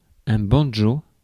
Ääntäminen
US : IPA : /ˈbæn.dʒoʊ/